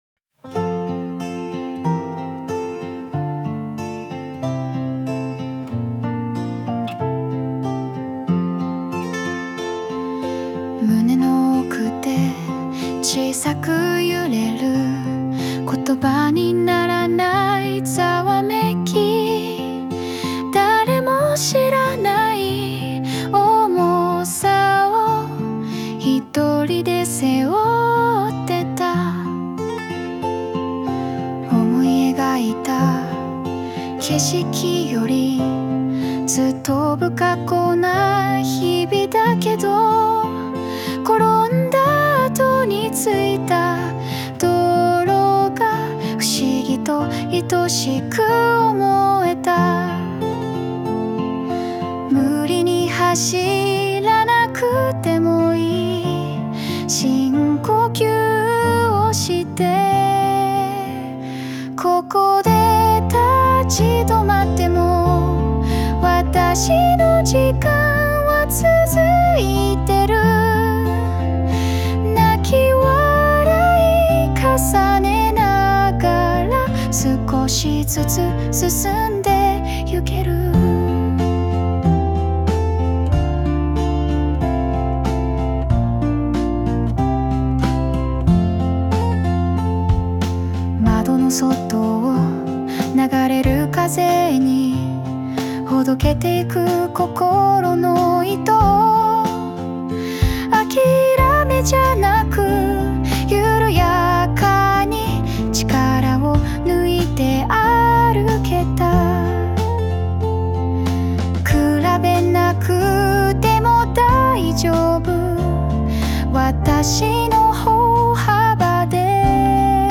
邦楽女性ボーカル著作権フリーBGM ボーカル
女性ボーカル（邦楽・日本語）曲です。
自分を励ますような優しい曲を目指して作りました✨